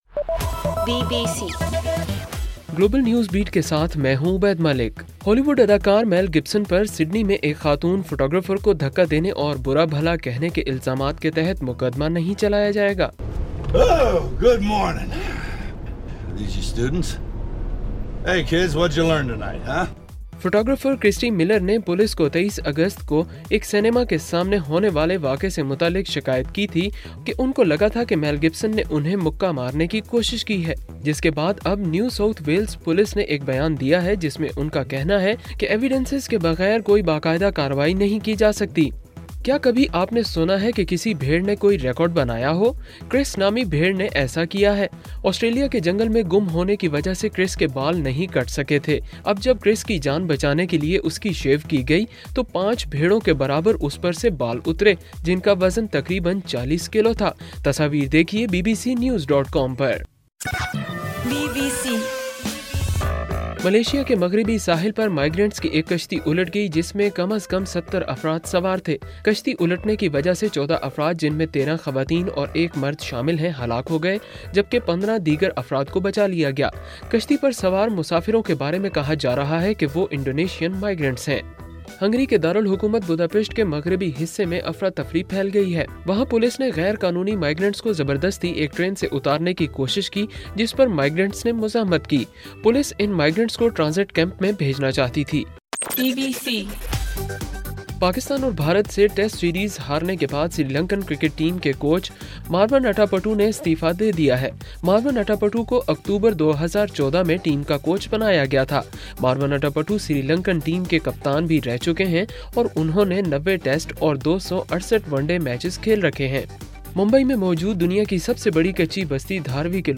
ستمبر 3: رات 10 بجے کا گلوبل نیوز بیٹ بُلیٹن